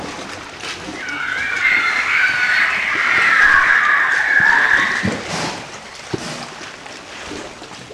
Audio files for the following article: Aerial Vocalizations by Wild and Rehabilitating Mediterranean Monk Seals (Monachus monachus) in Greece
Adult Scream
adultscream.wav